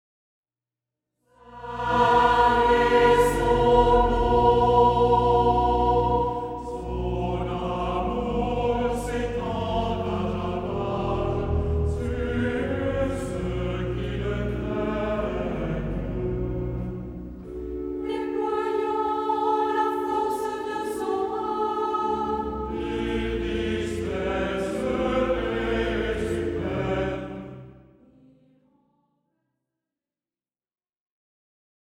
pour assemblée, choeur, solistes et orgue.
Format :MP3 256Kbps Stéréo